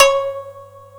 Index of /90_sSampleCDs/AKAI S-Series CD-ROM Sound Library VOL-1/3056SHAMISEN